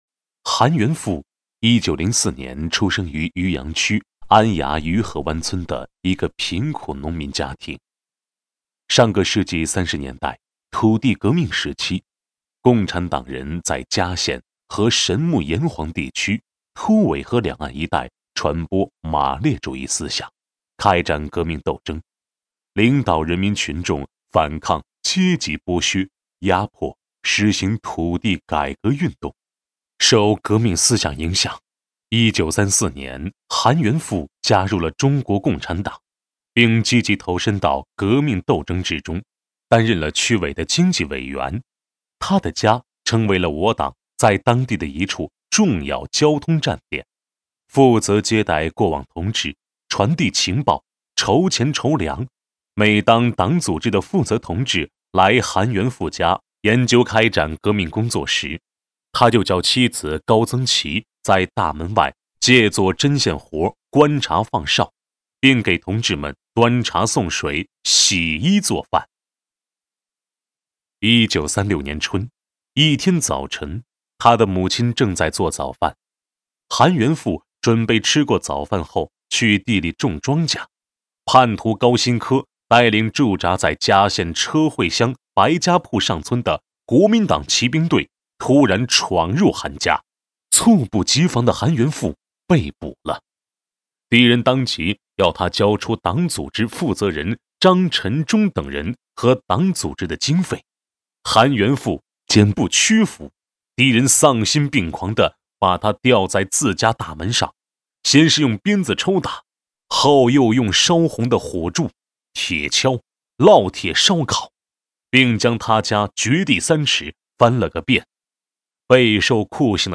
【红色档案诵读展播】革命先烈韩元富